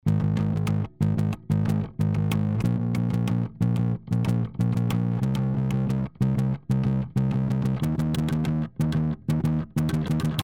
ベースの音をソロで、
• かかってる音